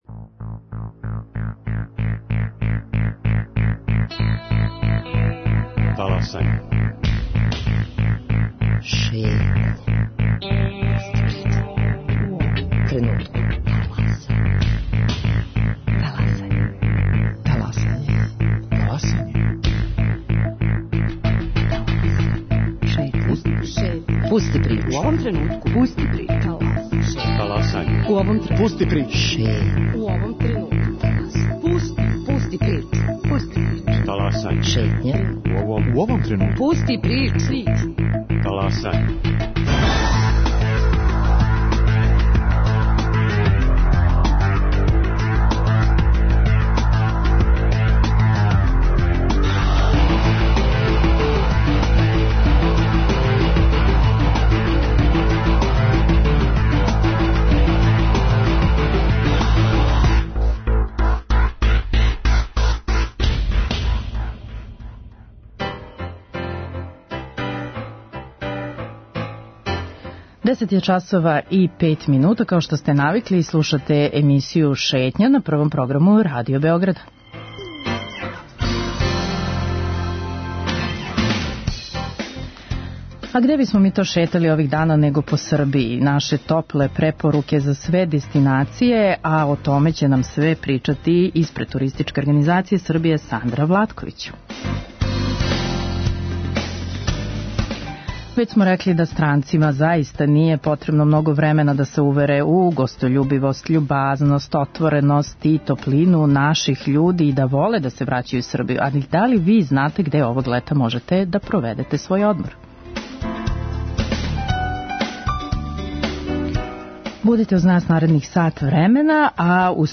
у телефонским укључењима